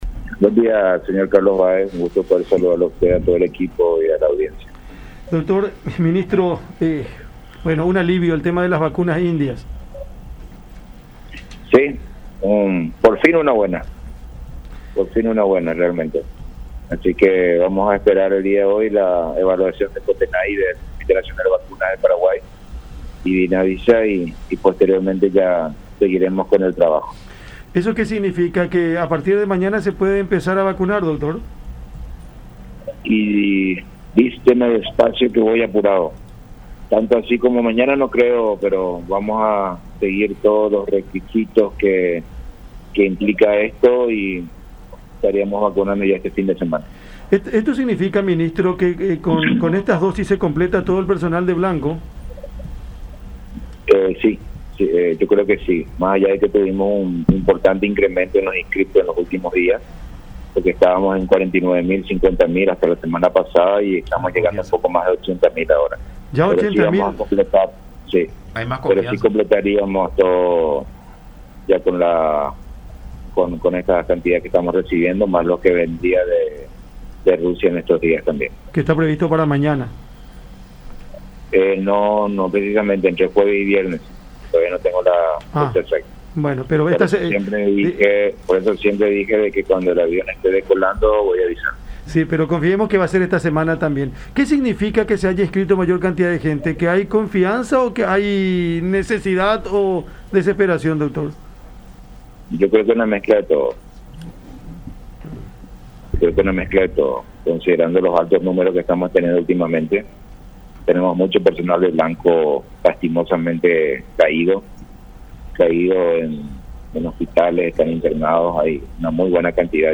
“¿COVAX? Bien, gracias”, respondió Borba en diálogo con La Unión al ser consultado sobre alguna contestación emitida al organismo internacional de la OMS.